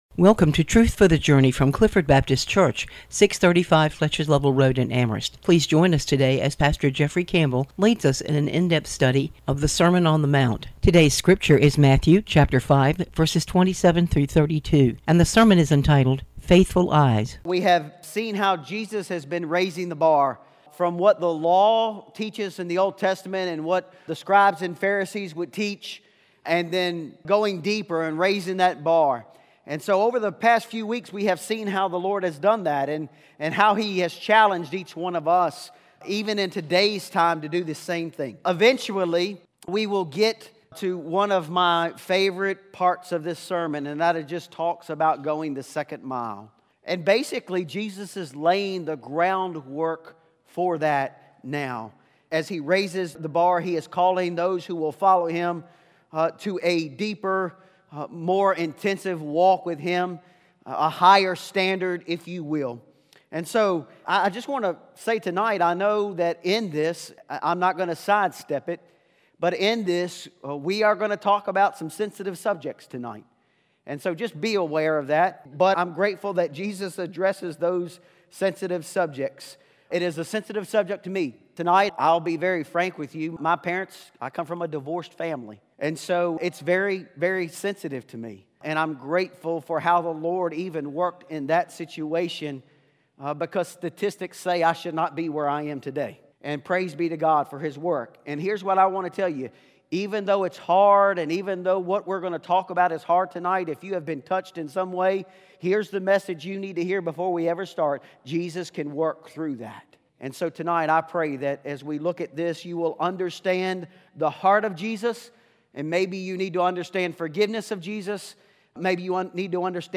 Sermon on the Mount: "Faithful Eyes", Matthew 5:27-32